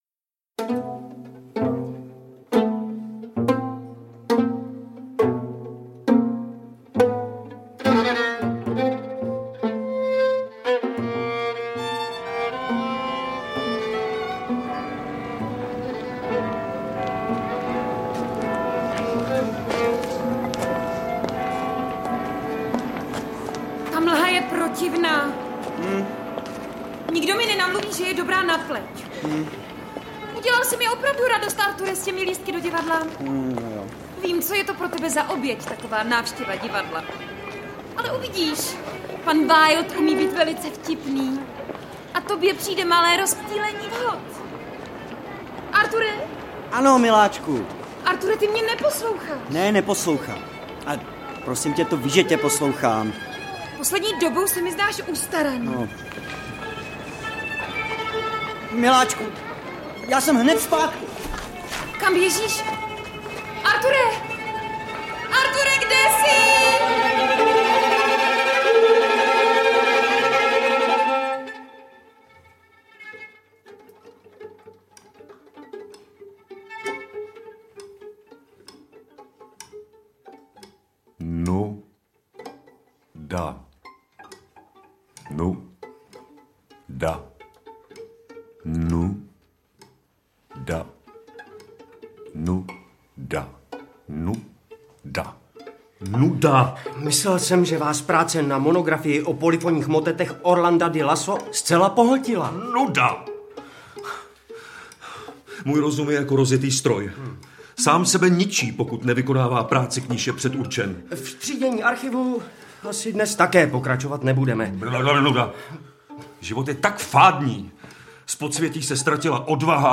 Rozhlasová hra založená na jedné z povídek, kterou sám Arthur C. Doyle zařadil mezi své vůbec nejoblíbenější.